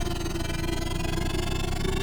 Speed_loop_3.ogg